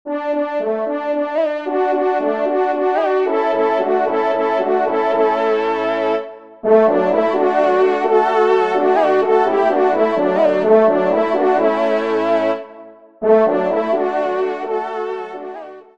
Genre : Musique Religieuse pour Quatre Trompes ou Cors
ENSEMBLE